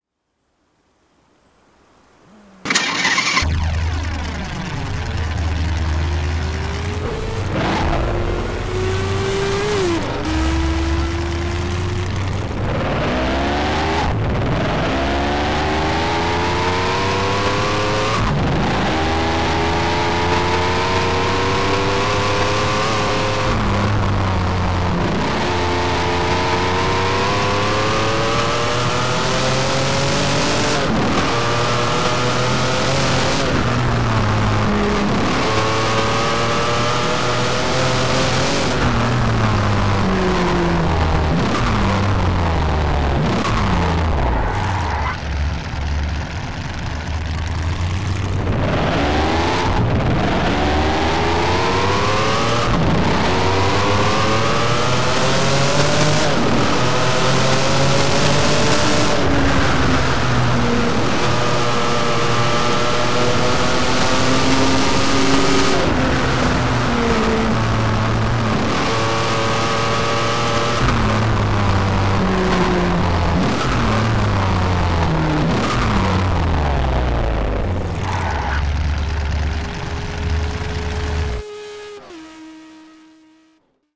TDU 1 - Sound mods